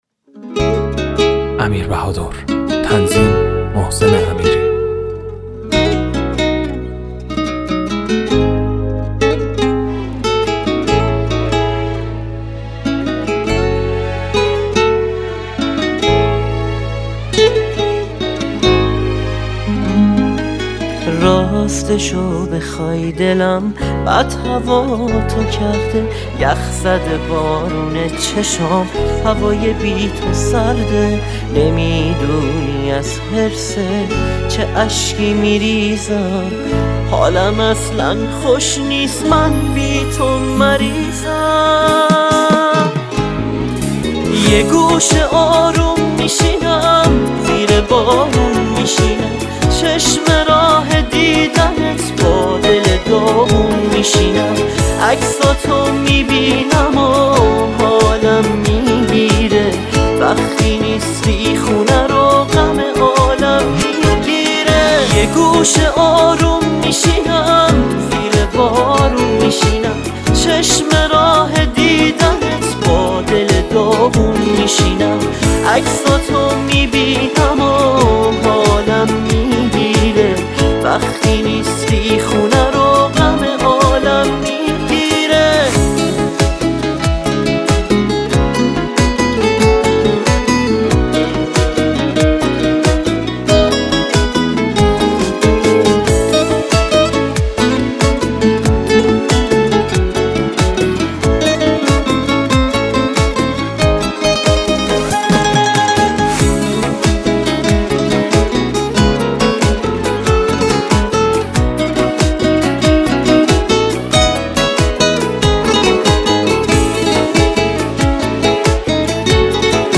گیتار